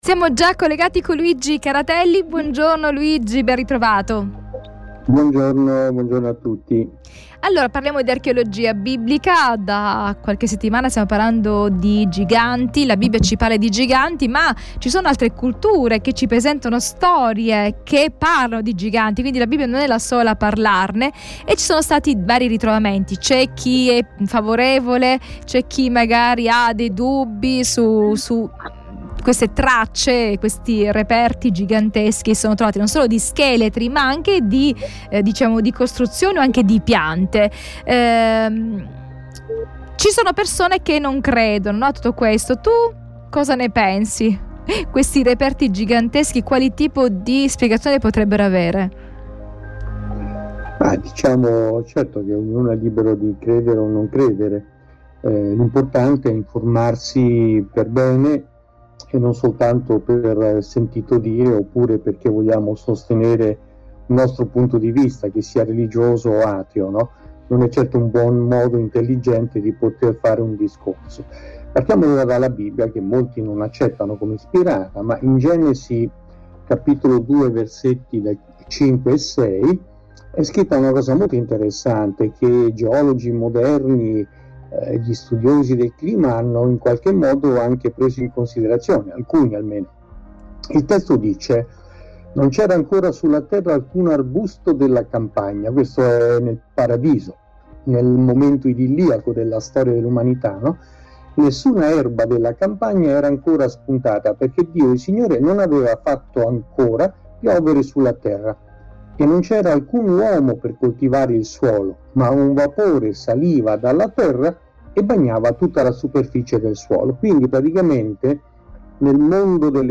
dialogo